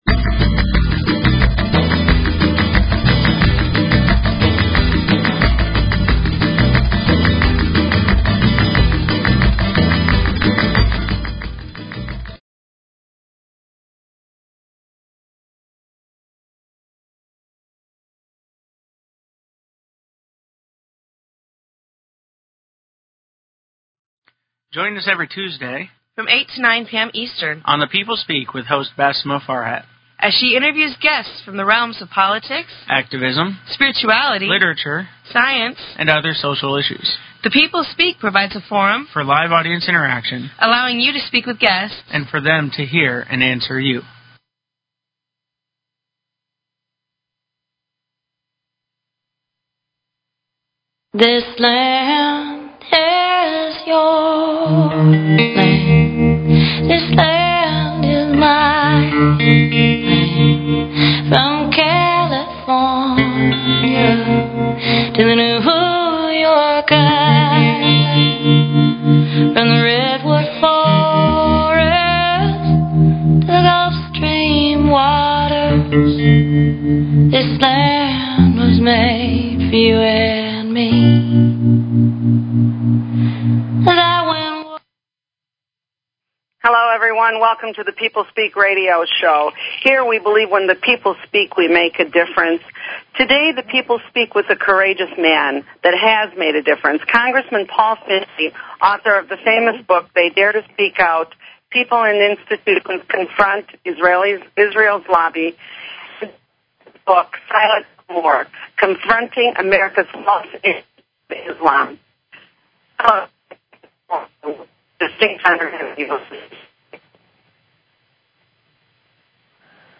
Talk Show Episode, Audio Podcast, The_People_Speak and Paul Findley on , show guests , about , categorized as
Guest, Paul Findley